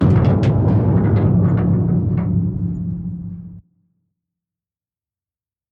StructureCrunch5.ogg